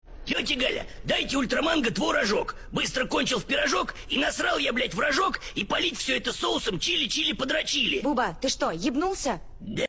buba pirozhok Meme Sound Effect
This sound is perfect for adding humor, surprise, or dramatic timing to your content.